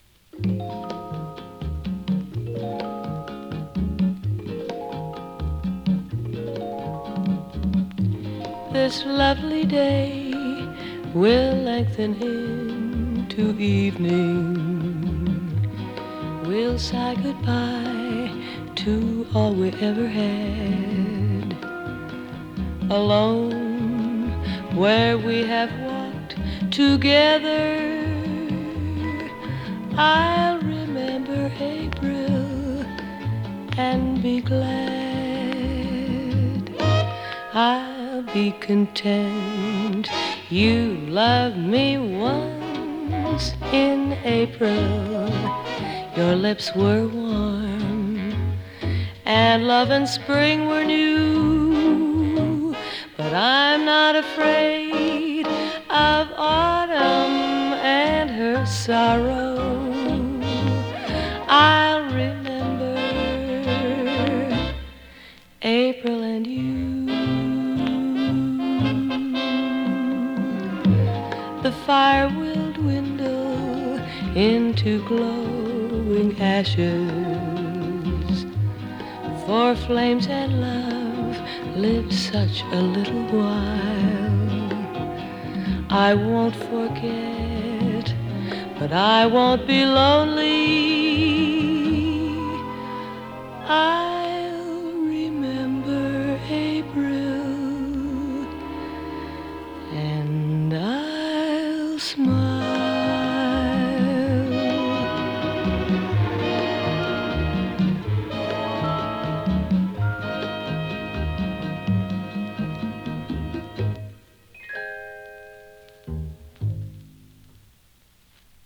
カリフォルニアはサンタローザ出身の白人シンガー。
所々軽いパチ・ノイズ。